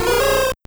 Cri de Hoothoot dans Pokémon Or et Argent.